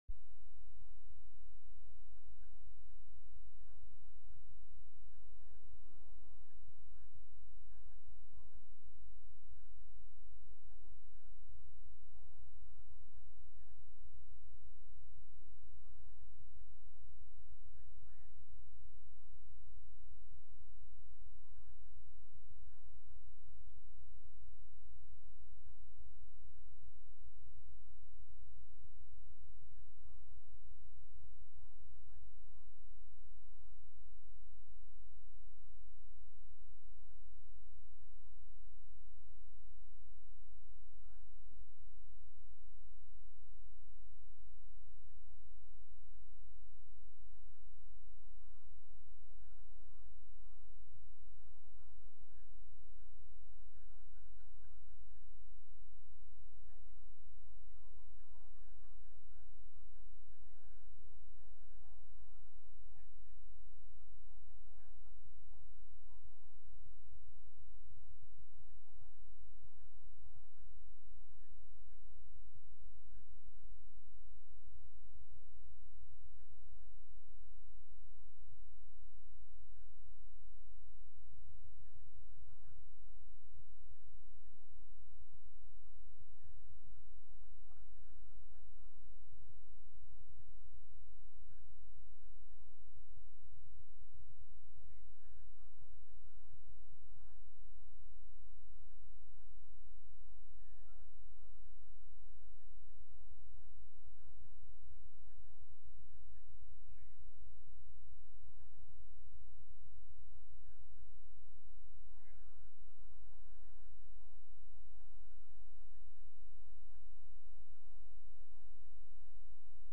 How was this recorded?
From Series: "Sunday Worship" Sunday-Service-5-15-22.mp3